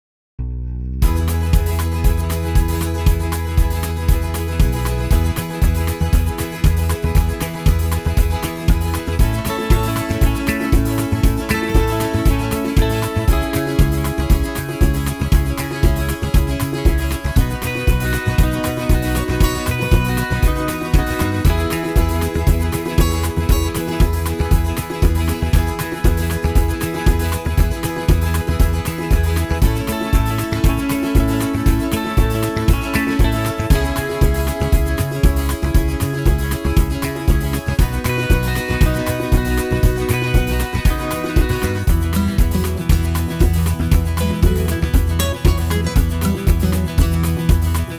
Unique Backing Tracks
key - E - vocal range - (optional B low note) E to E